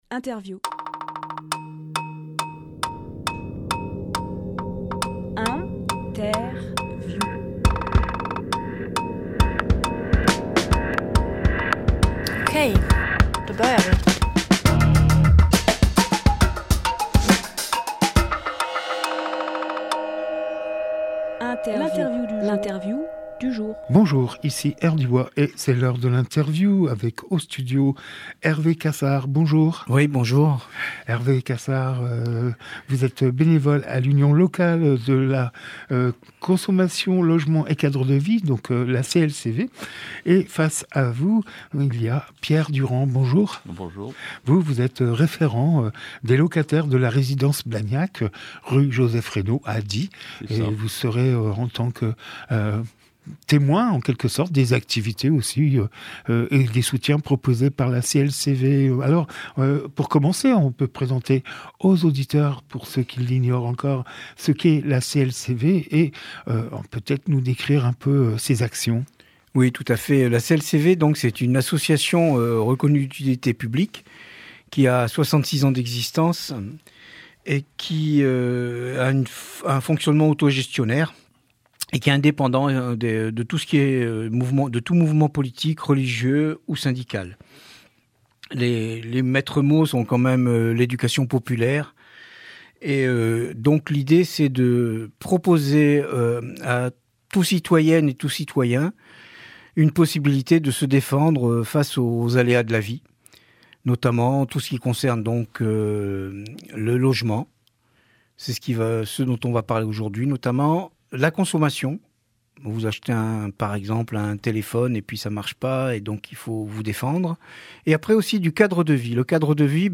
Emission - Interview Consommation Logement et Cadre de Vie et le collectif de Blagnac Publié le 18 janvier 2025 Partager sur…
lieu : Studio Rdwa